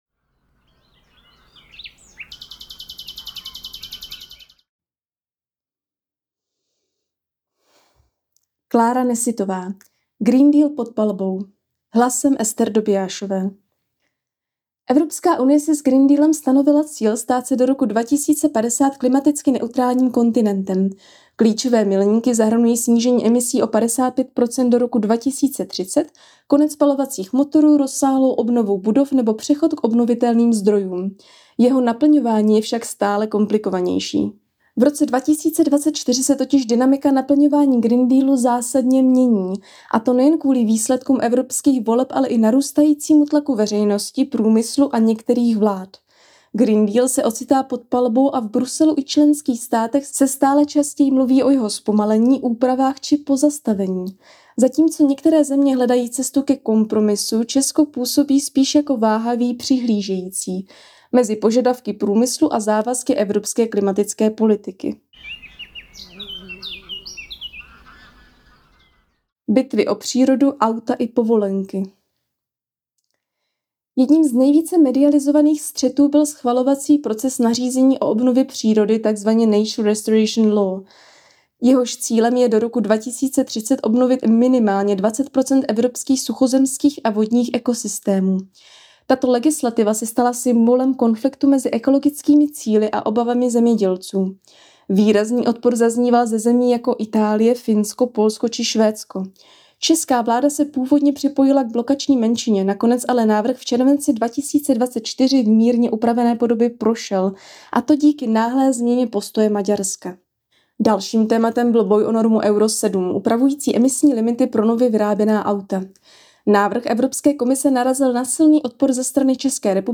Moc krásný hlas